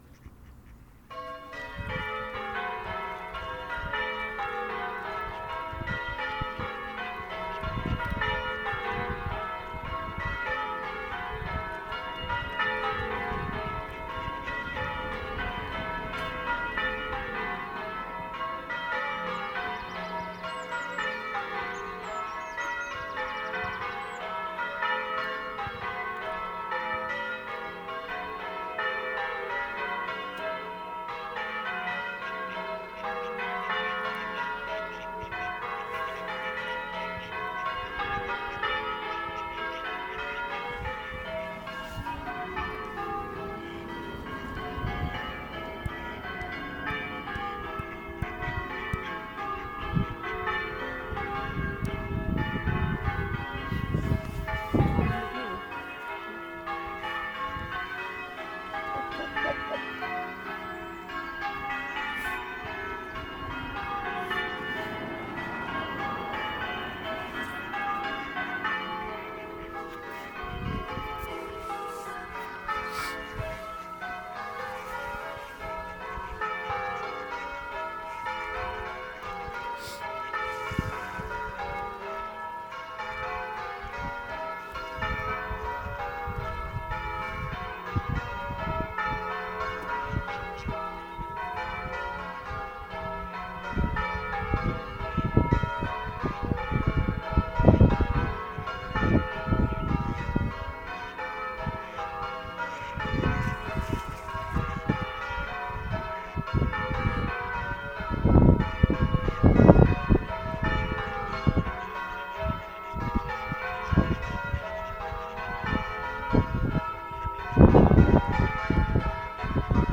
Recordings of Church Bells
Part of the Haddenham Bellringers ringing a quarter peal of Grandsire Doubles to mark the Queen’s 90th Birthday